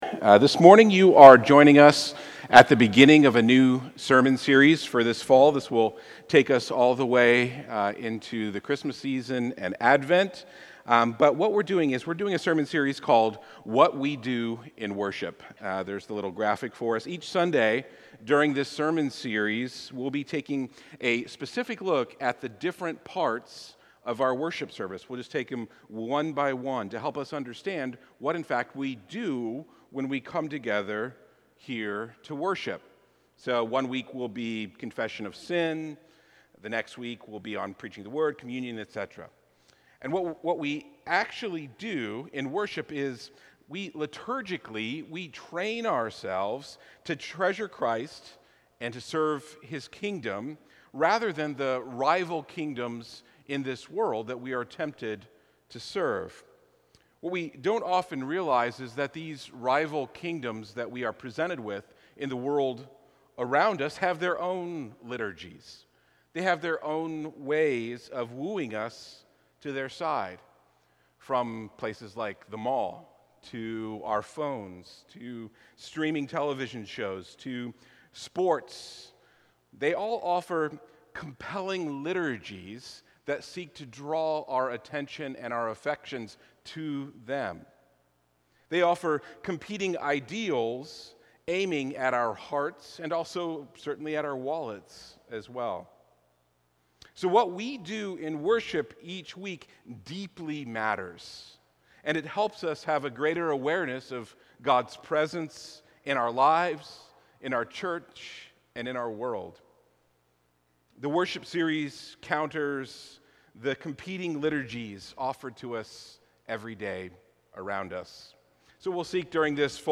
In this sermon we’ll consider the role of the Call to Worship, think about what worship actually is, and think about what Psalm 100 (a very traditional and often used Call to Worship) is calling us to do.